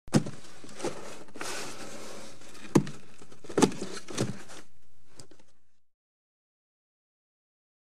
Cardboard Movement And Friction